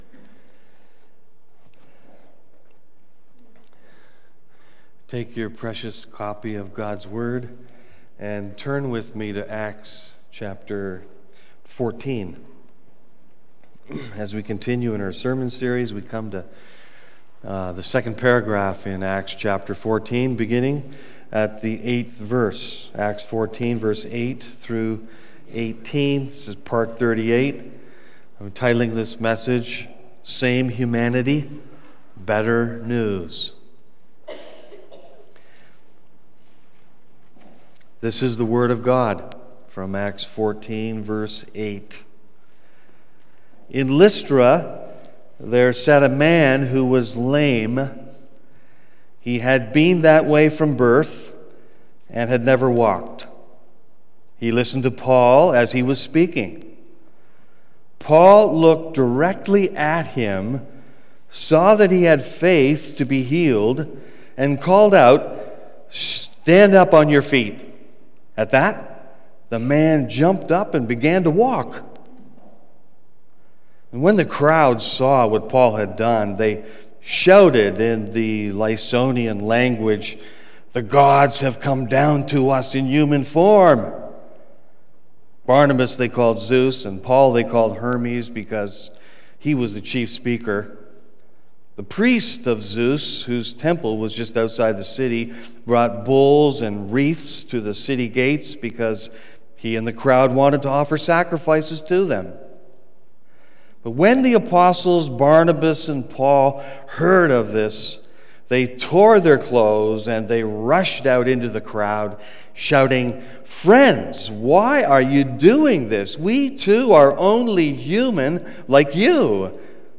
Part 38 BACK TO SERMON LIST Preacher